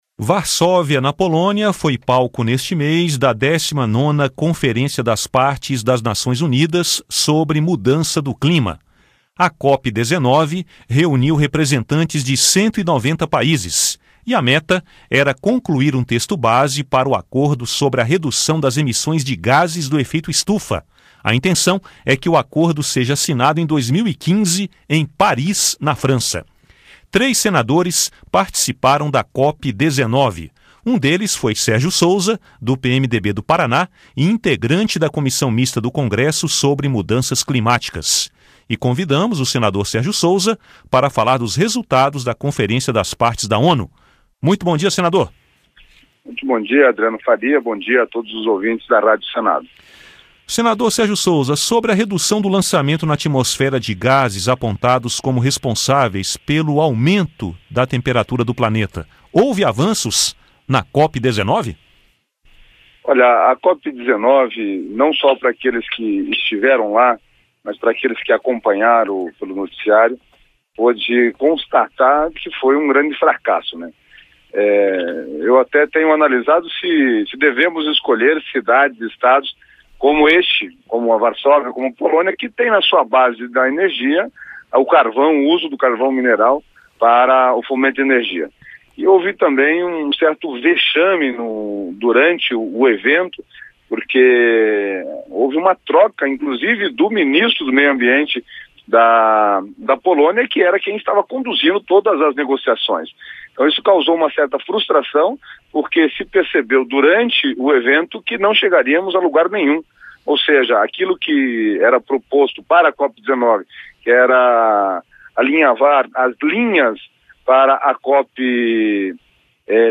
Entrevista com o senador Sérgio Souza (PMDB-PR).